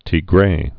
(tē-grā)